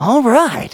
Kibera-Vox_Happy4.wav